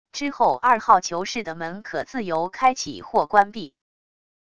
之后二号囚室的门可自由开启或关闭wav音频生成系统WAV Audio Player